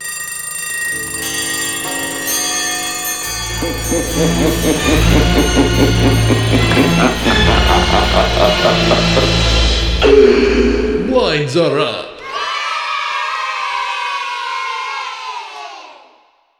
Misc sound effects
announcing new level                     x